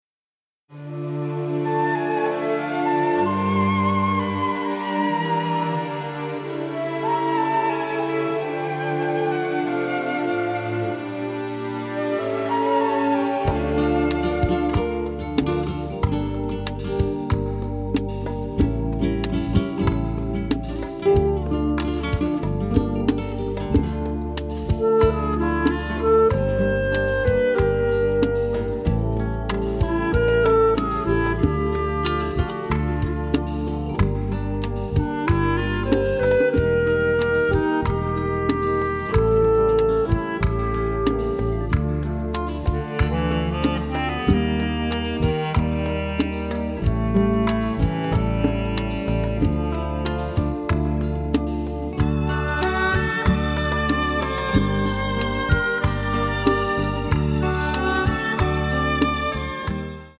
mastered from the original tapes